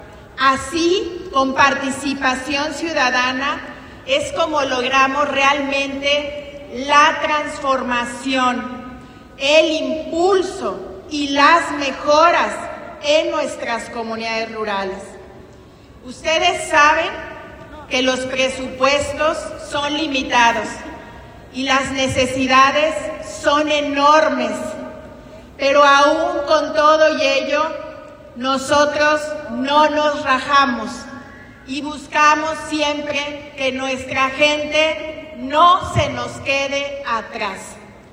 AudioBoletines
Lorena Alfaro García, presidenta municipal